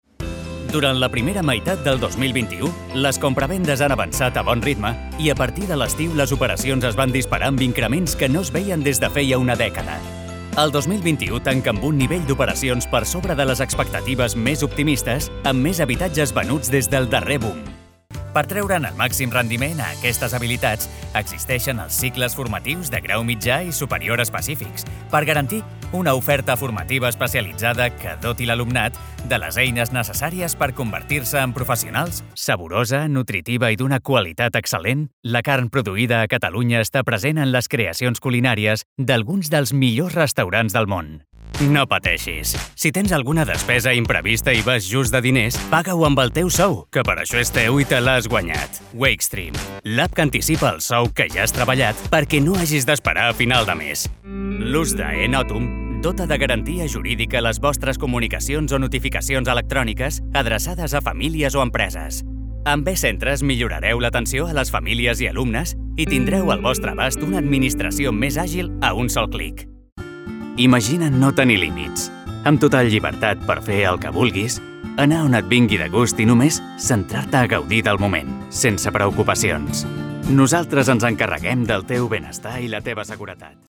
Jeune, Naturelle, Distinctive, Urbaine, Cool
Vidéo explicative